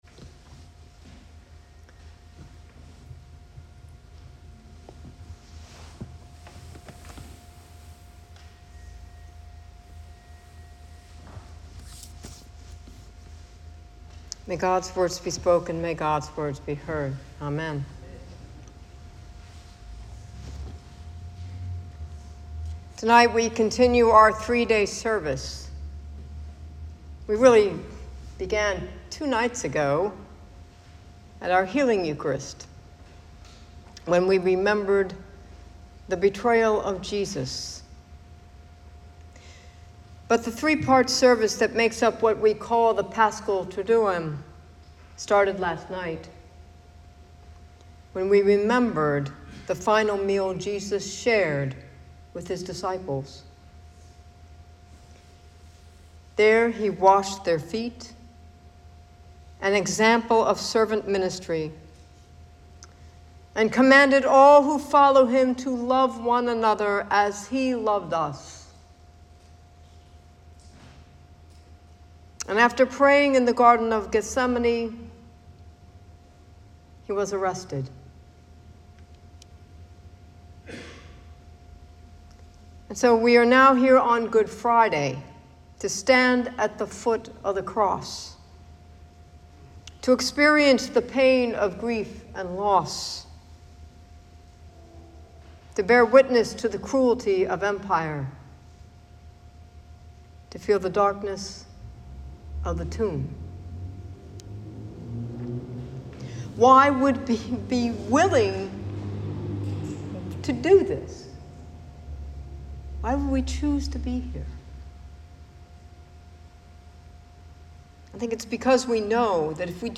Sermon Podcast
Good Friday